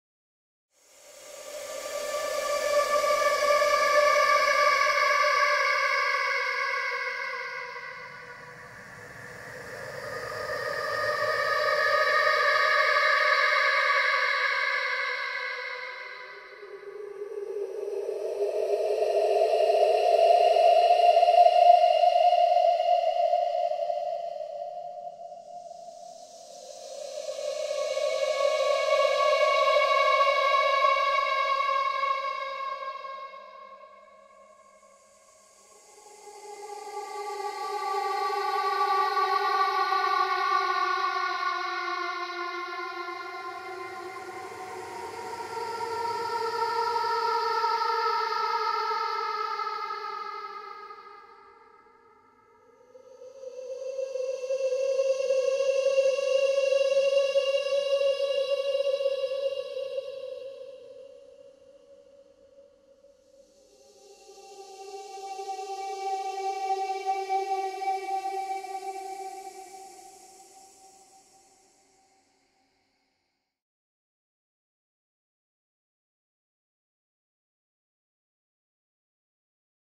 جلوه های صوتی
دانلود صدای روح 2 از ساعد نیوز با لینک مستقیم و کیفیت بالا
برچسب: دانلود آهنگ های افکت صوتی انسان و موجودات زنده دانلود آلبوم صدای ترسناک روح از افکت صوتی انسان و موجودات زنده